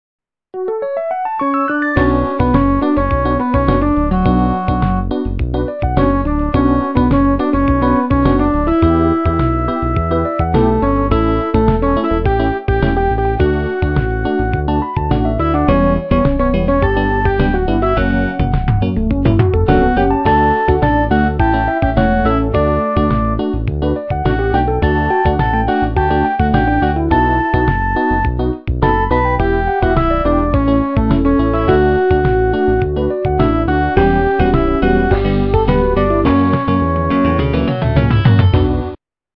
- русская эстрада
качество понижено и присутствуют гудки
полифоническую мелодию